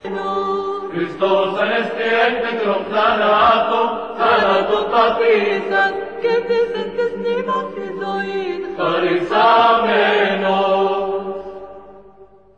Click here to download the Audio Clip of the Greek version of the prayer in the voice of Fairouz